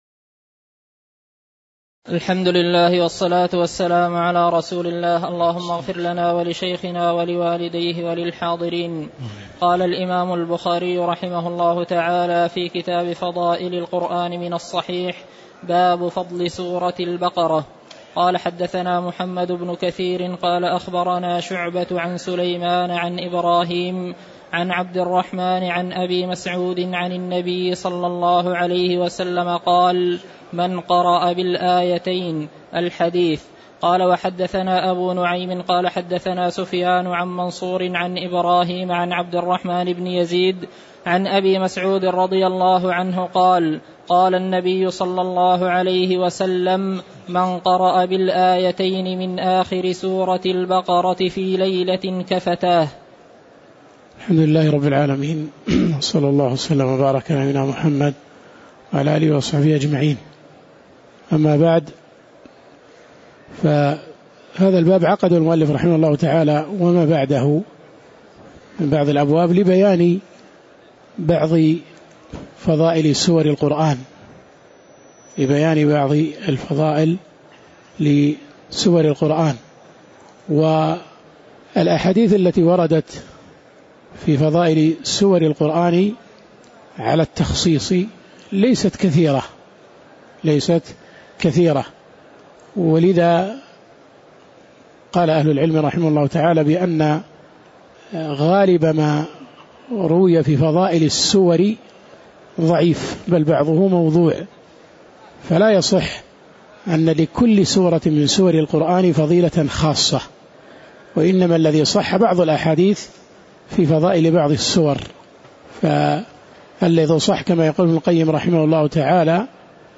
تاريخ النشر ١٦ رمضان ١٤٣٩ هـ المكان: المسجد النبوي الشيخ